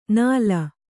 ♪ nāla